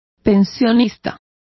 Complete with pronunciation of the translation of boarders.